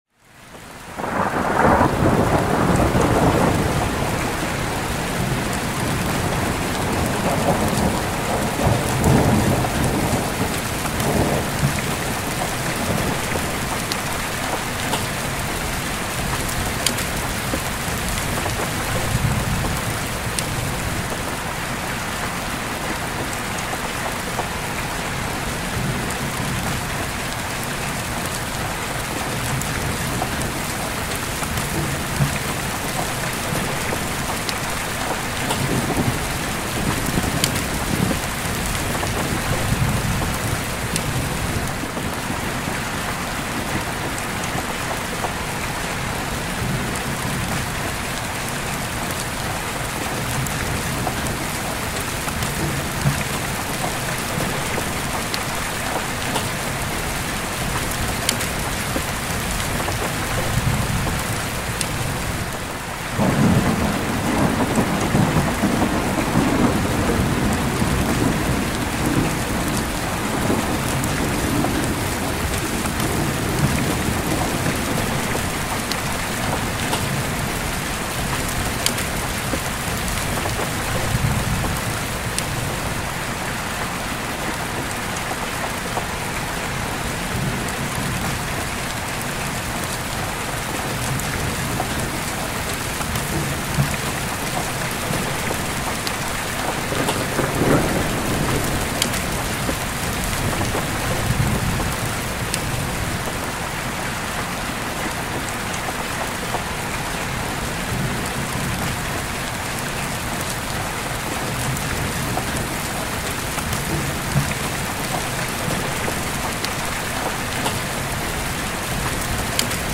Open Window Rainstorm – Deep Sleep Ambience
Every episode of Rain Sounds is carefully crafted to deliver high-quality ambient rain recordings that promote deep sleep, reduce anxiety, and enhance mindfulness.